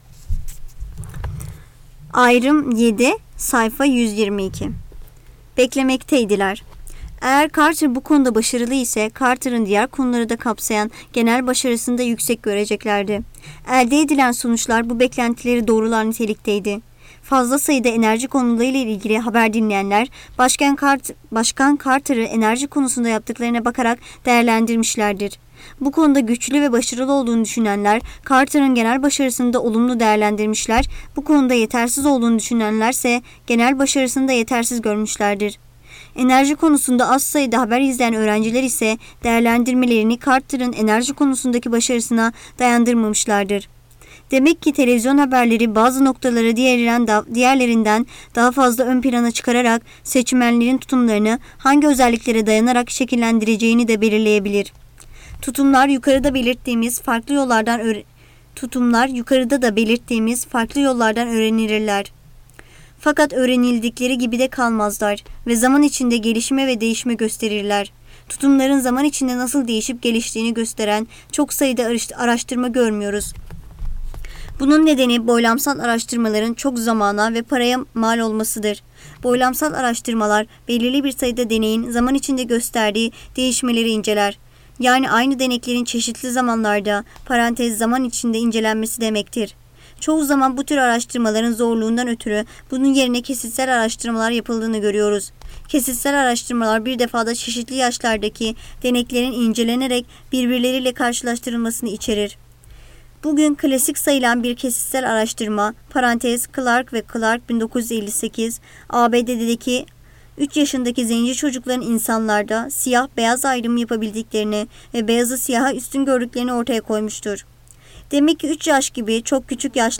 SESLİ KİTAP PAU